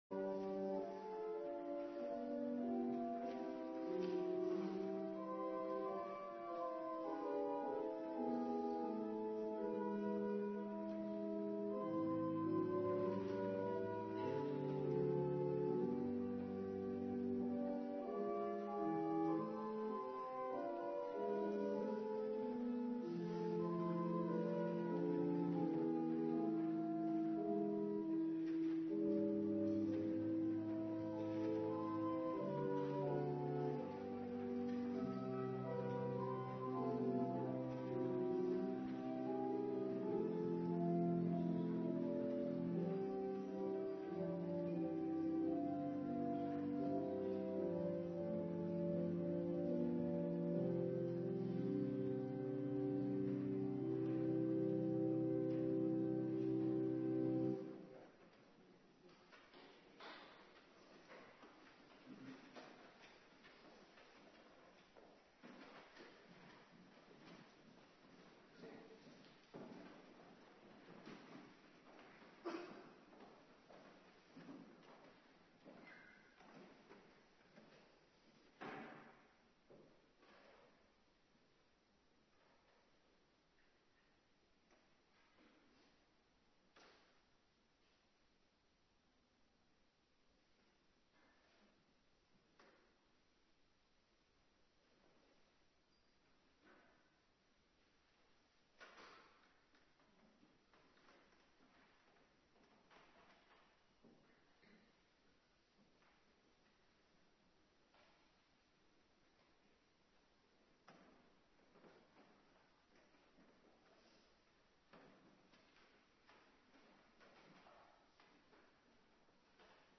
Woensdagavonddienst